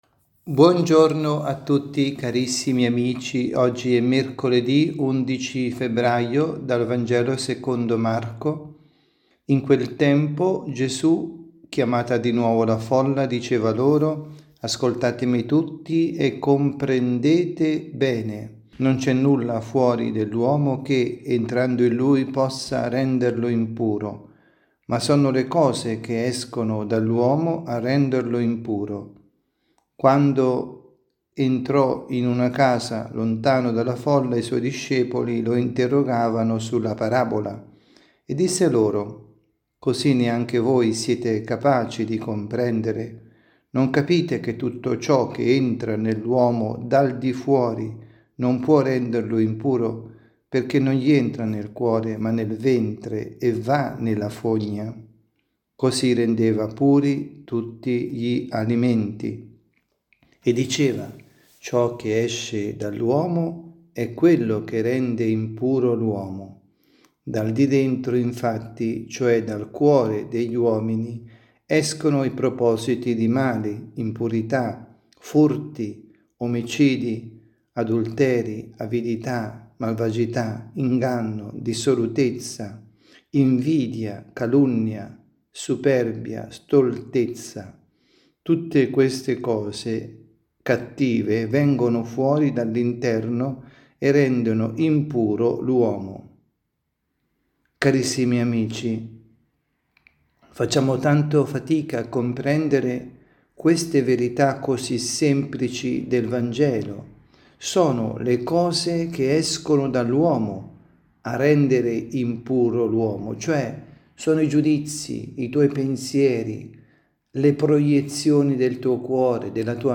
Catechesi
dalla Basilica di San Nicola – Tolentino